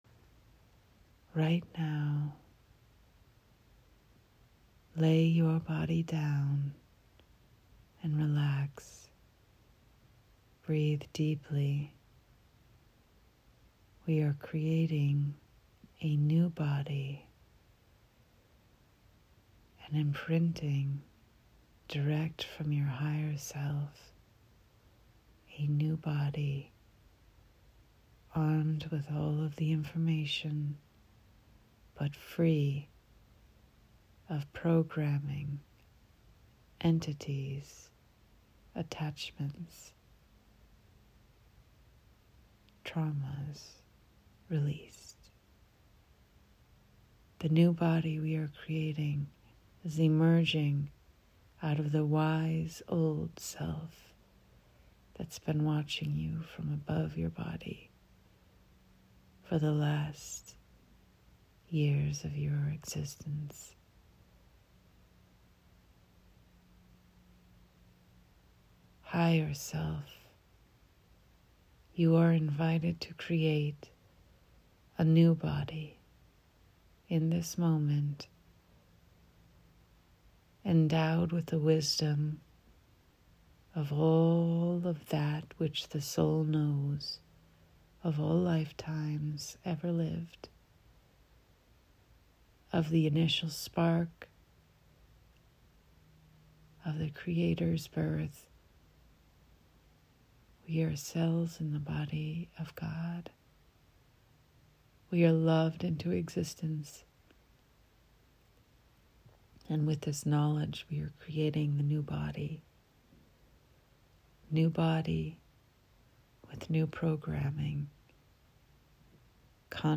Guided Meditations